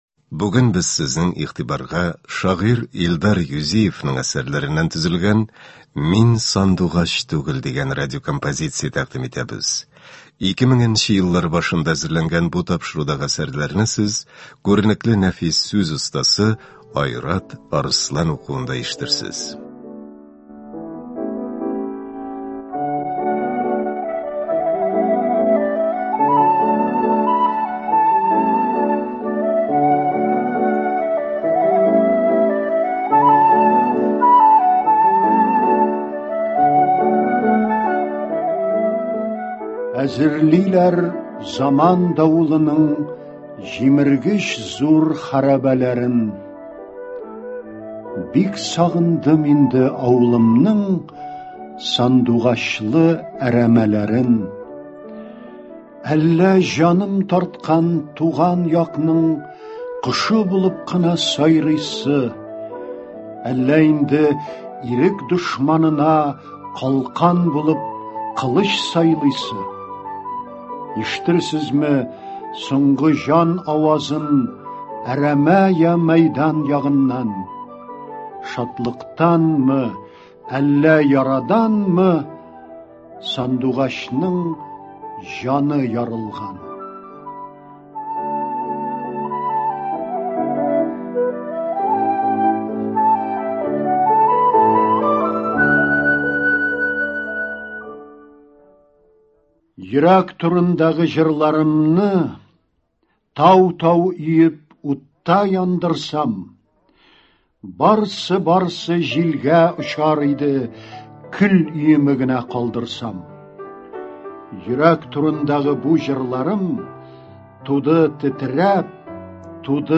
Радиокомпозиция.